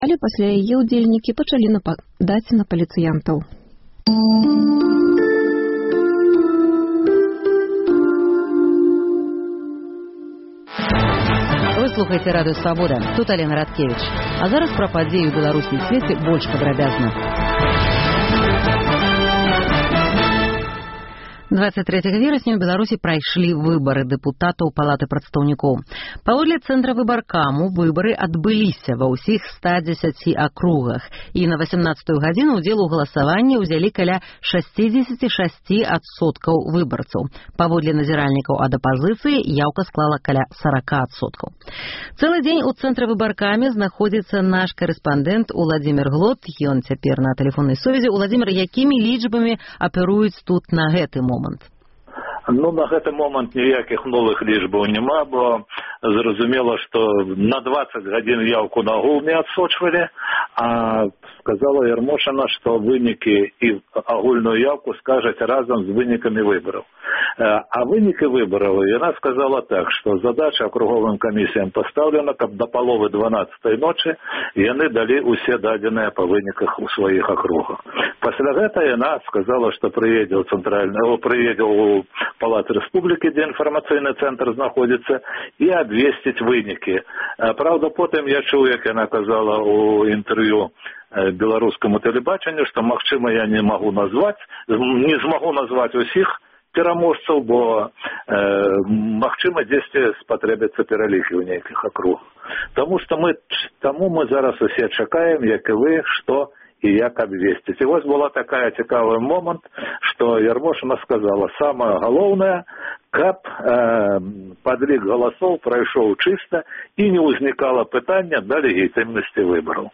Навіны Беларусі і сьвету. Паведамленьні нашых карэспандэнтаў, званкі слухачоў, апытаньні ў гарадах і мястэчках Беларусі